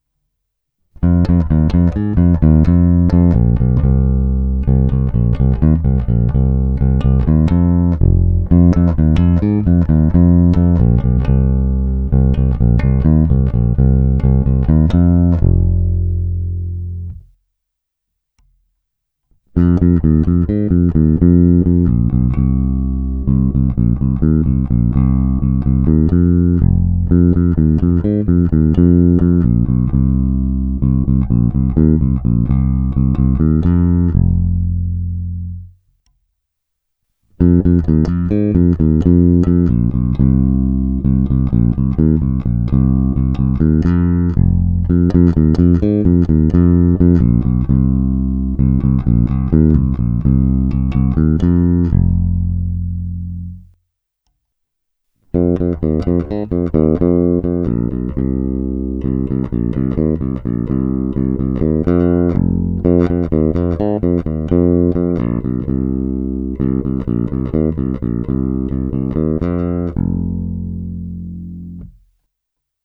Není-li uvedeno jinak, následující nahrávky jsou provedeny rovnou do zvukové karty a s plně otevřenou tónovou clonou. Nahrávky jsou jen normalizovány, jinak ponechány bez úprav.